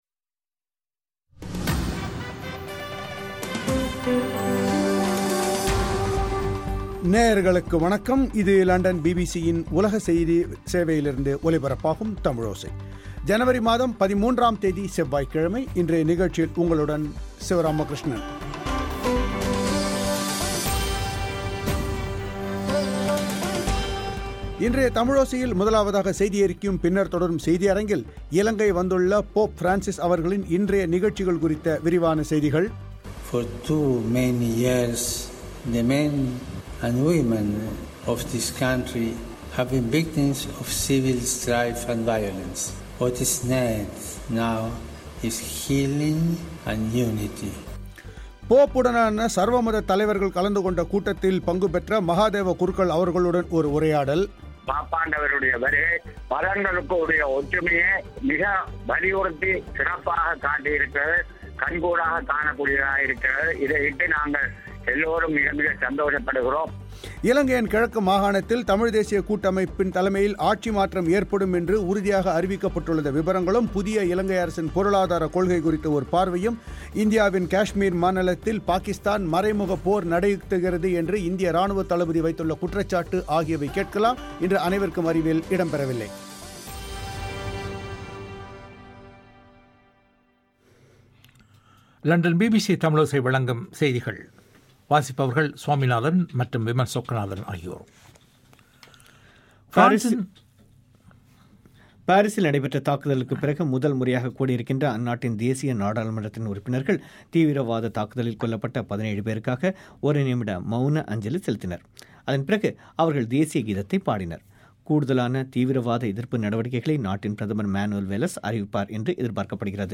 ஒரு உரையாடல்